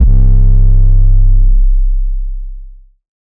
SOUTHSIDE_808_grindhouse_C.wav